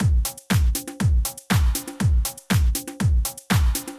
Drumloop 120bpm 05-A.wav